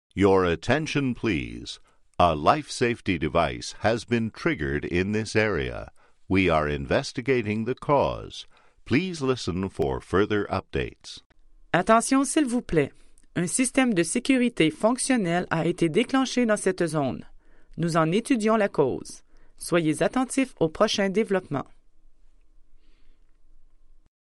H&S – AIRPORT ALARMS & EVACUATION ALERT
STAGE 1 – LIFE SAFETY DEVICE TRIGGERED
STAGE-1-LIFE-SAFTEY-DEVICE-TRIGGERED.mp3